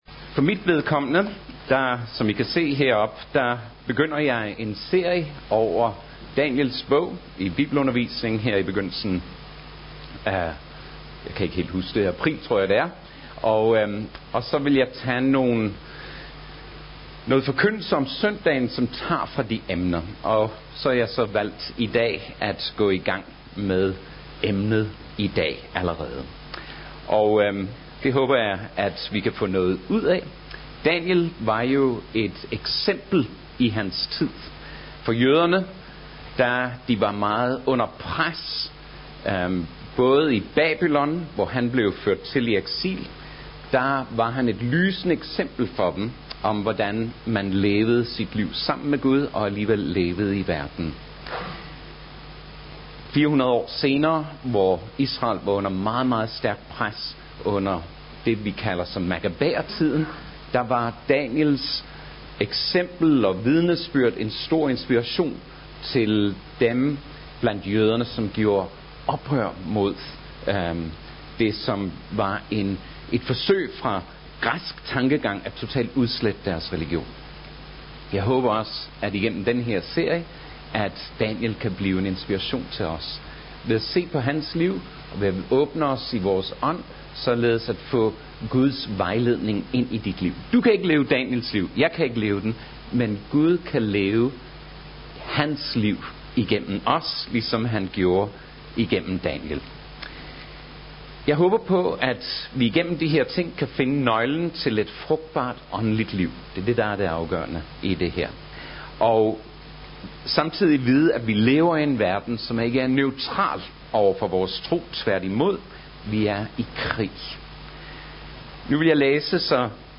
2. marts 2014 Type af tale Prædiken Slip Bibelen Løs Daniels Bog Bibeltekst Daniels Bog MP3 Hent til egen PC